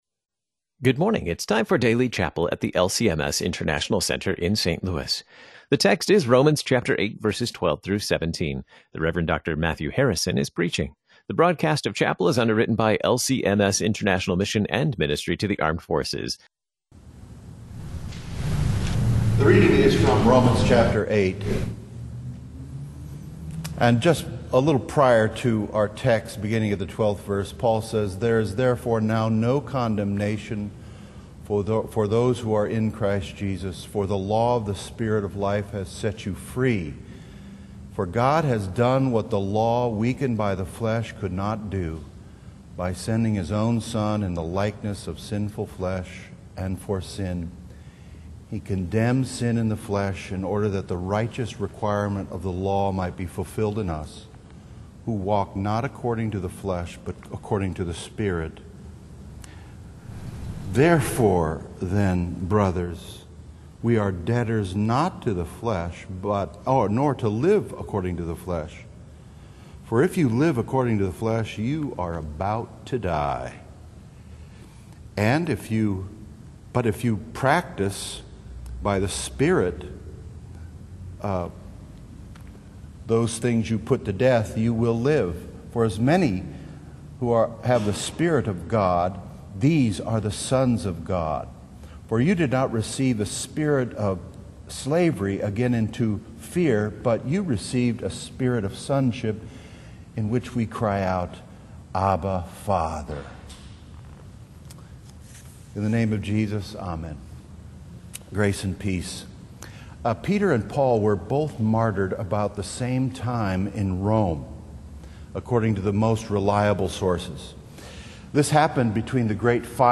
Rev. Dr. Matthew Harrison gives today's sermon based on Romans 8:12-17.